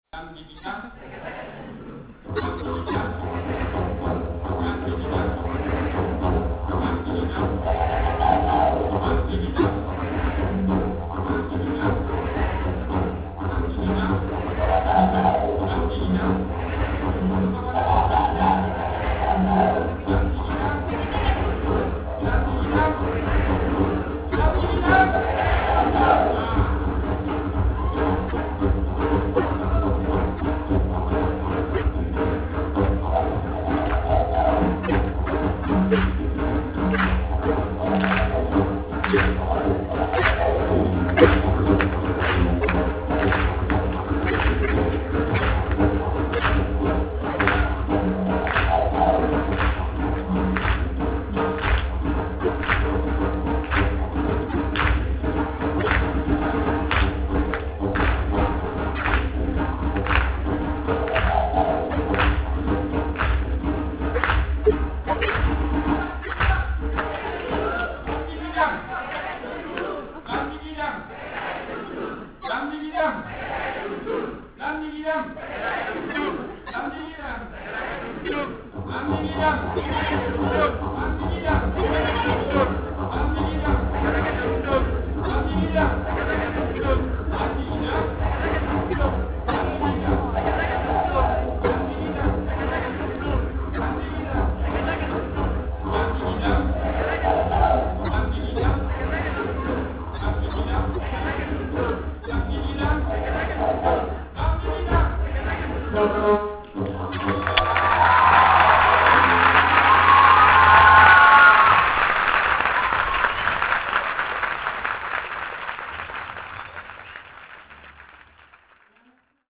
une musique qui bouge!